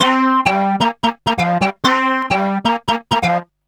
___BOTTLES 6.wav